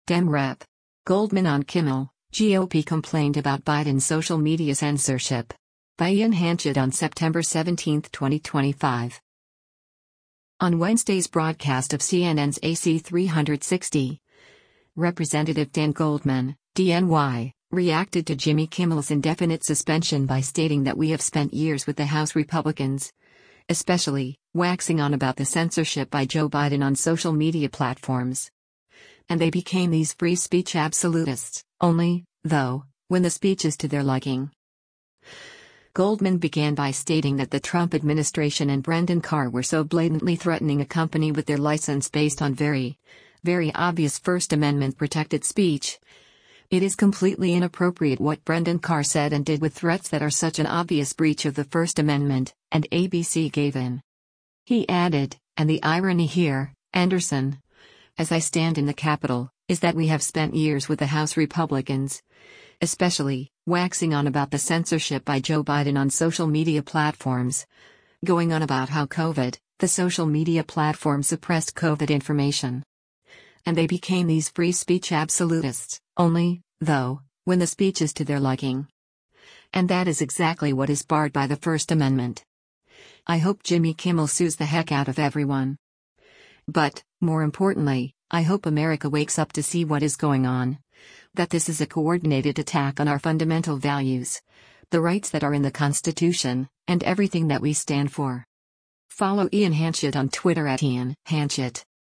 On Wednesday’s broadcast of CNN’s “AC360,” Rep. Dan Goldman (D-NY) reacted to Jimmy Kimmel’s indefinite suspension by stating that “we have spent years with the House Republicans, especially, waxing on about the censorship by Joe Biden on social media platforms,” “And they became these free speech absolutists, only, though, when the speech is to their liking.”